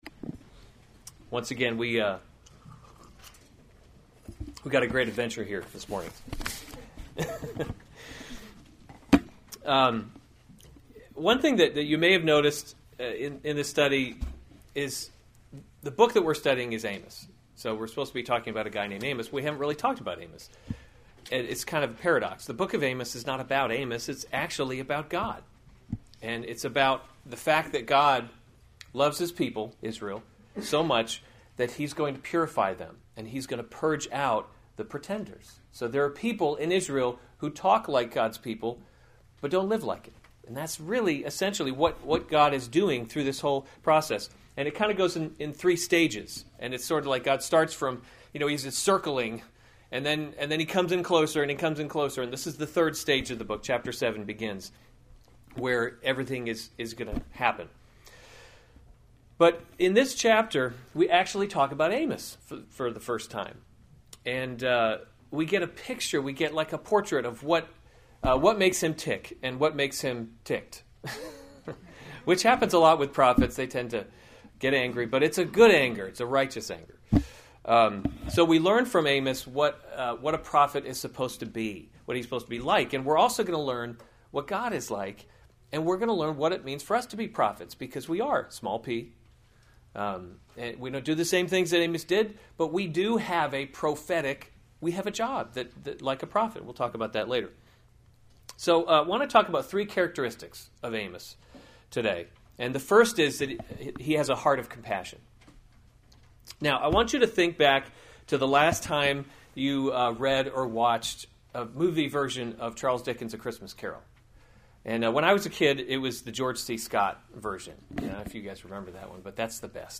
November 7, 2015 Amos: He’s Not a Tame Lion series Weekly Sunday Service Save/Download this sermon Amos 7:1-17 Other sermons from Amos Warning Visions 7:1 This is what the Lord God […]